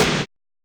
ARTY SNR.wav